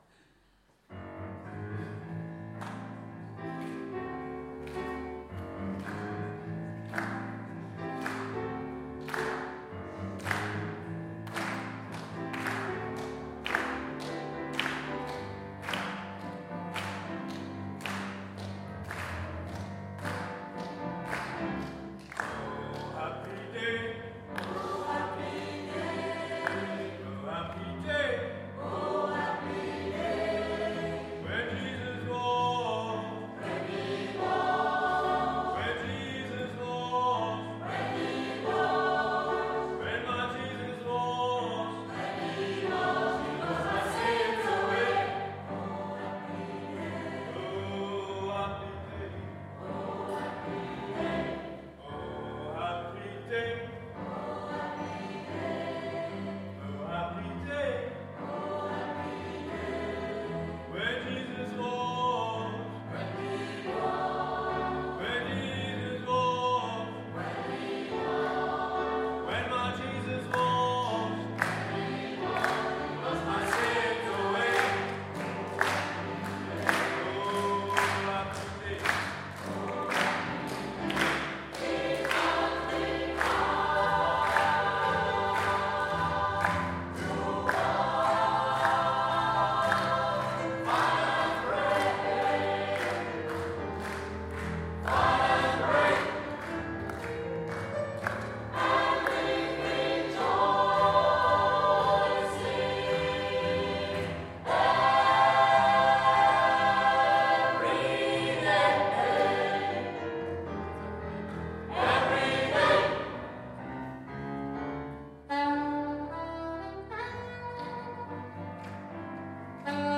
Kirchliche und weltliche Musik, dazu ältere und moderne Stücke gemischt, kombiniert mit einem guten Zweck, das ist Circle of Songs. Dabei sorgen Instrumentalisten aus der Gruppe, bestehend aus Piano, Violine, Saxofon sowie Gitarre zusätzlich für die musikalische Begleitung.
Live-Aufnahmen